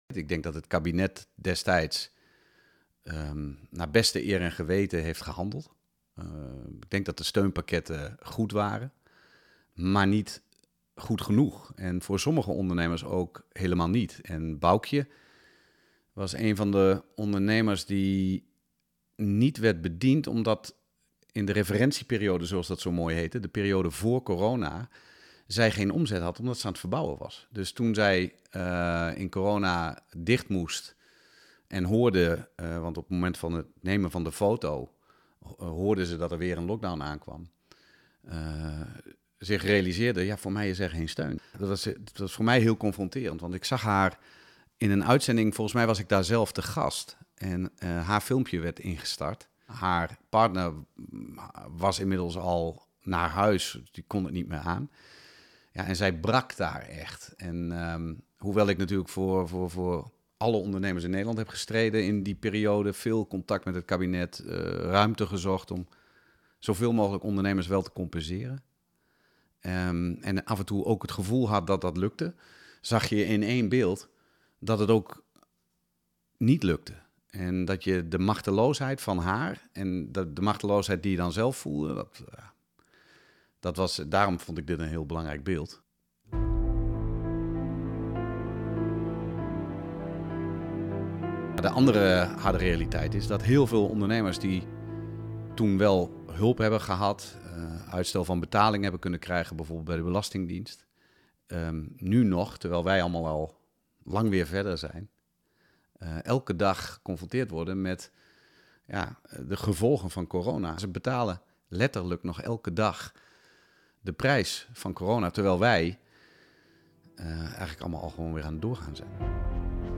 Verteller